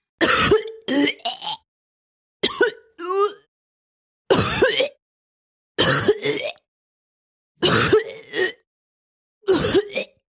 Tiếng Nôn ọe giọng Nữ, con gái
Thể loại: Tiếng con người
Description: Tổng hợp các tiếng nôn ọe phụ nữ, mang thai, đau ốm, female vomit sound effect ...
tieng-non-oe-giong-nu-con-gai-www_tiengdong_com.mp3